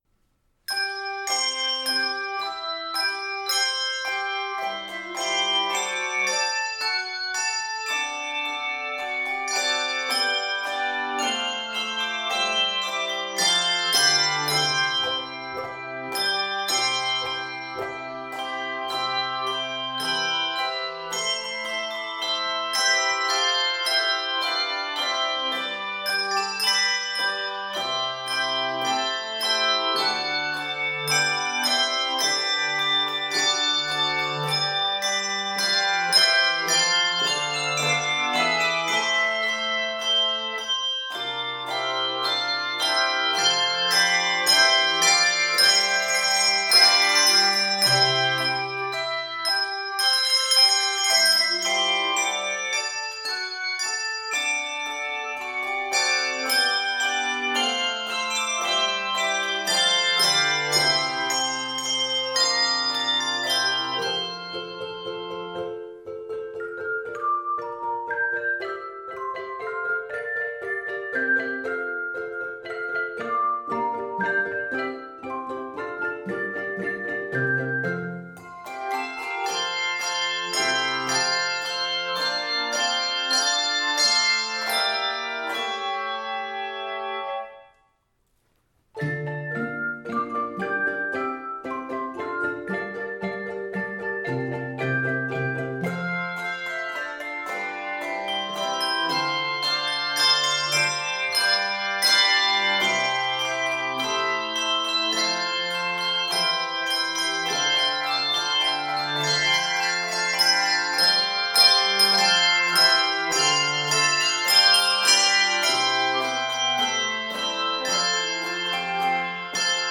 Keys of C Major and F Major.